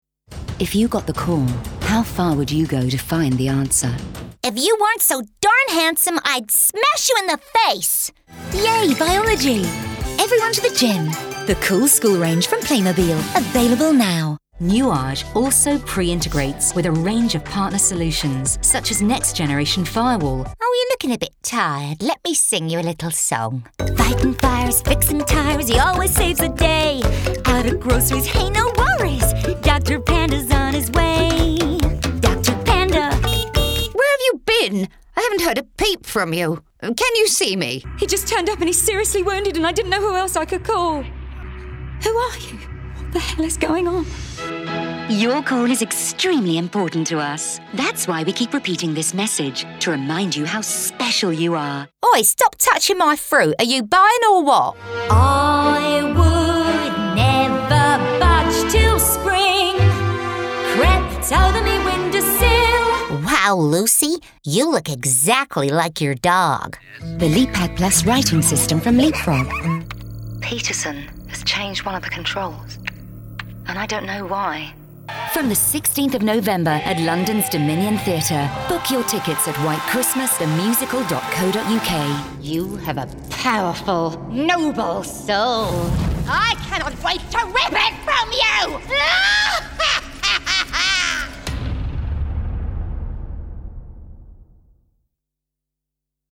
Demo
Adult
Warm, Trustworthy, Conversational, Characters, Comedy, Versatile
british rp | natural